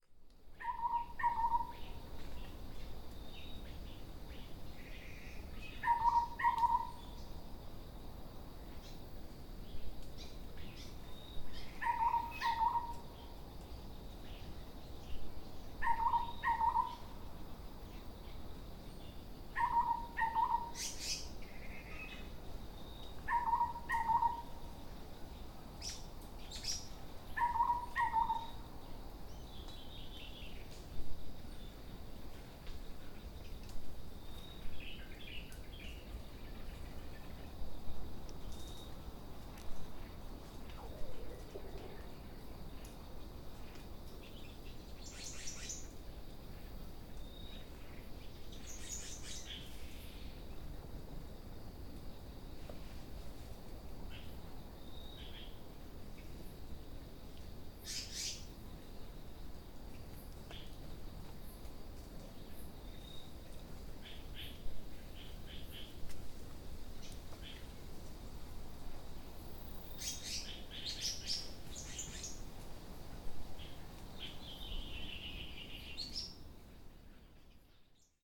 37-墾丁社頂公園-小彎嘴 | 台灣聲景協會 Soundscape Association of Taiwan
37-墾丁社頂公園-小彎嘴.mp3